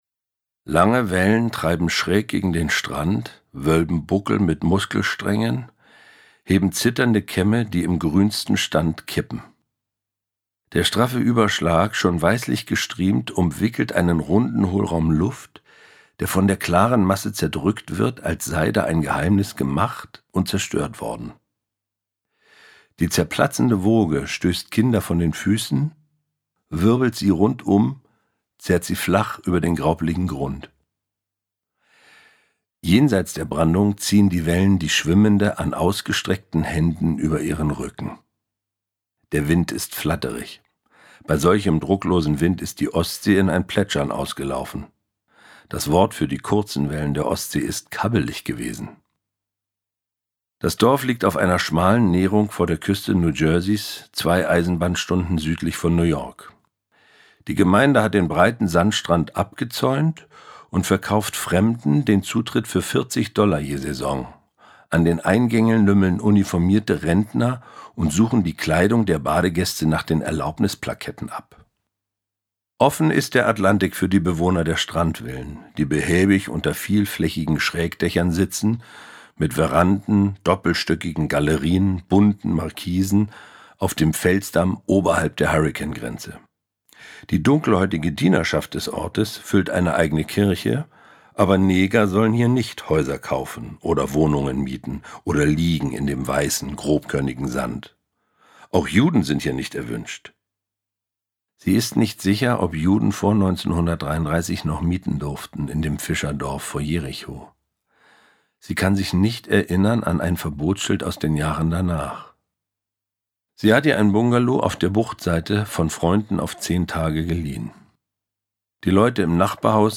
Charly Hübner, Caren Miosga (Sprecher)
Ungekürzte Lesung mit Charly Hübner und Caren Miosga auf 6 mp3-CDs